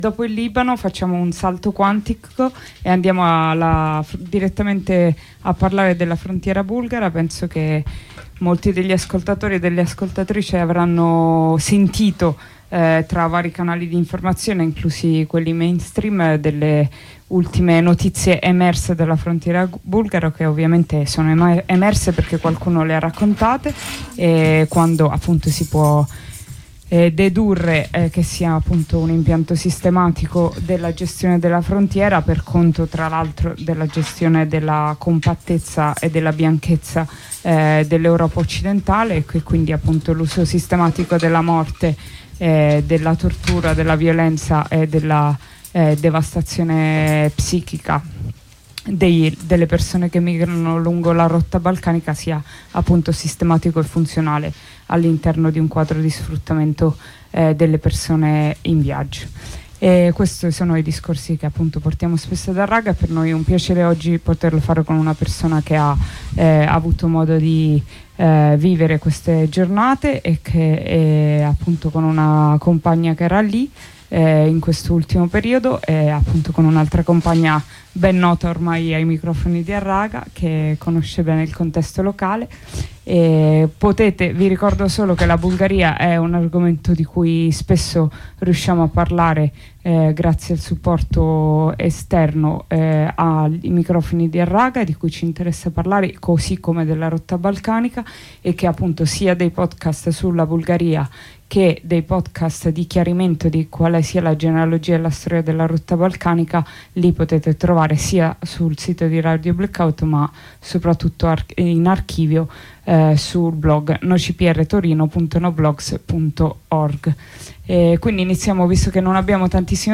Ai microfoni di Harraga, in onda su Radio Blackout, con una compagna del collettivo Rotte Balcaniche abbiamo approfondito gli ultimi, tragici eventi accaduti al confine bulgaro-turco, di cui ha parlato anche la stampa nostrana.